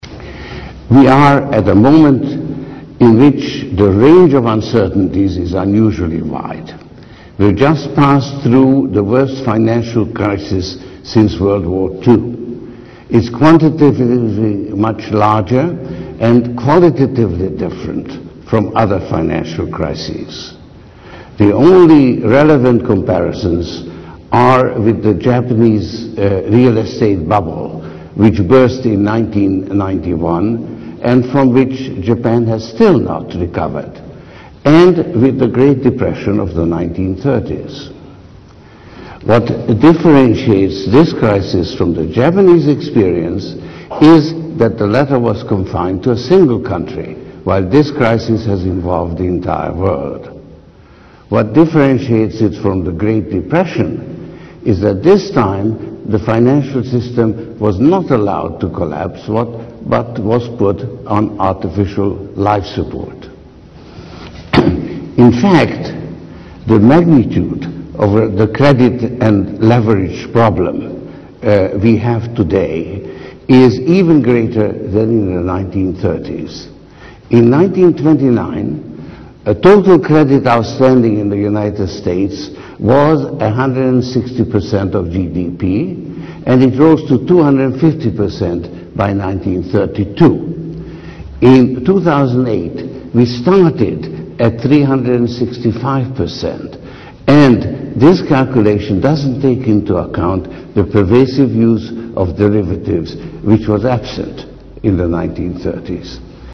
这些财富精英大多是世界著名公司的CEO，在经济领域成就斐然。在演讲中他们或讲述其奋斗历程，分享其成功的经验，教人执着于梦想和追求；或阐释他们对于公司及行业前景的独到见解，给人以启迪和思考。